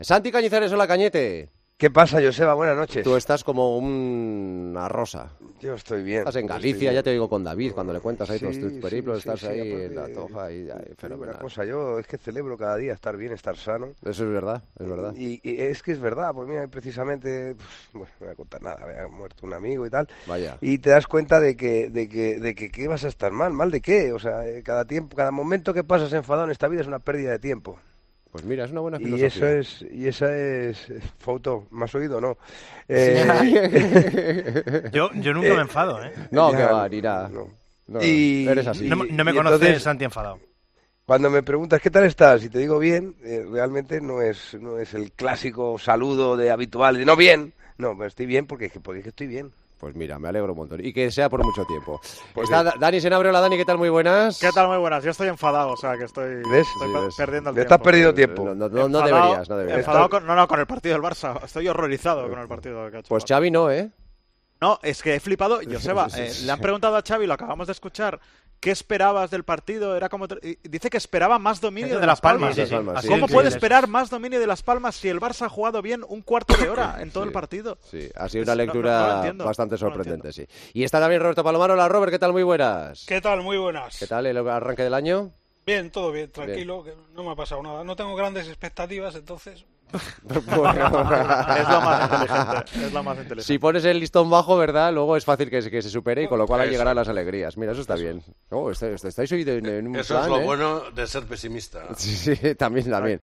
En la ronda de presentaciones y primeras reflexiones de los comentaristas de este jueves, los oyentes de El Partidazo de COPE se encontraron con un tono sereno de Santi Cañizares, que lanzó un mensaje con un destinatario concreto.